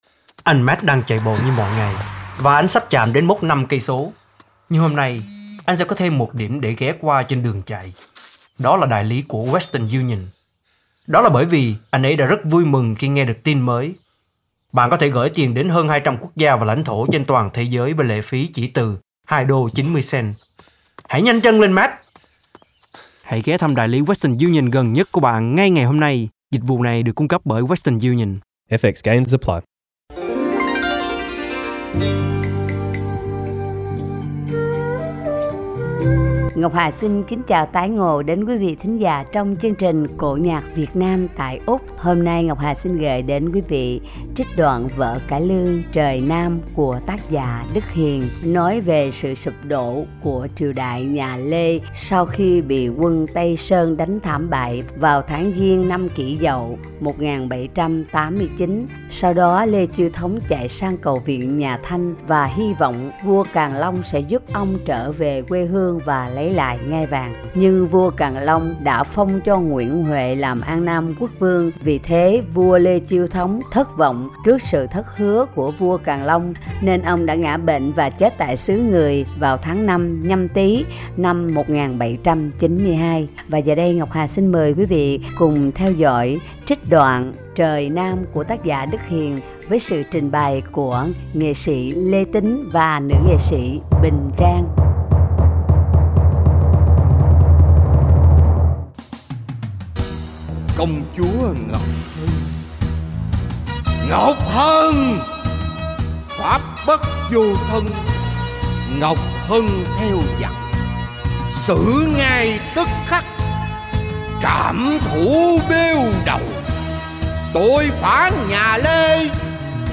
Trích đoạn vở Cải Lương